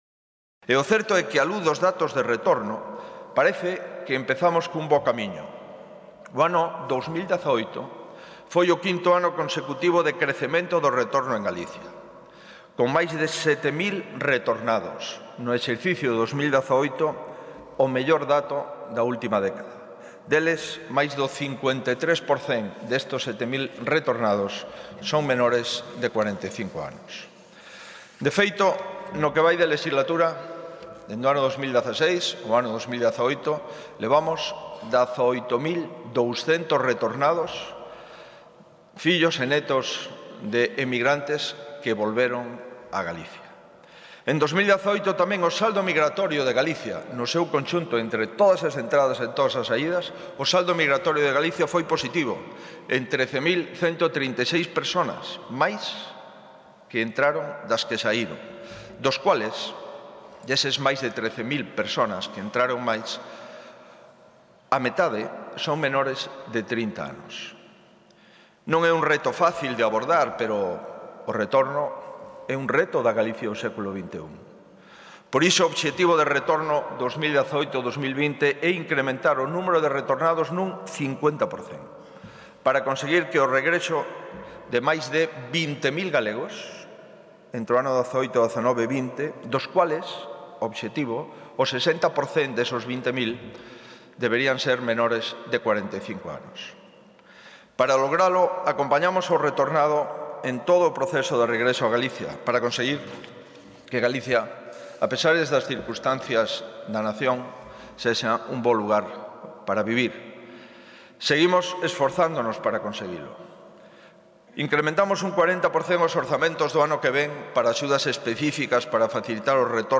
Audio | Declaracións do presidente da Xunta de Galicia na inauguración do XII Pleno do CCG
Durante o acto inaugural do XII Pleno do Consello de Comunidades Galegas, Feijóo aseverou que esta iniciativa, que foi crecendo, afecta a todos os ámbitos e conta cun investimento global, espallado por todos os departamentos da Xunta, ata 2020, que ascende a 235 millóns, para desenvolver medidas específicas e máis de 220 transversais.